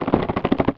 CONSTRUCTION_Rocks_02_mono.wav